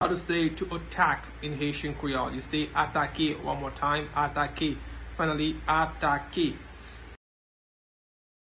Pronunciation:
Listen to and watch “Atake” pronunciation in Haitian Creole by a native Haitian  in the video below: